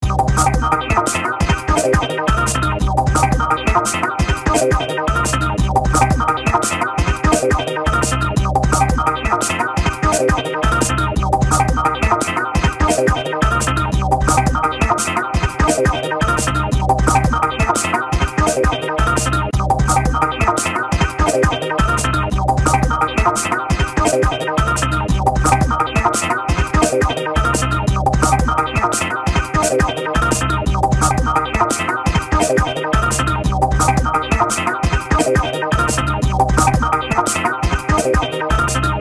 繰り返しのループサウンドであり、テンポを上げたスピード感のある音です。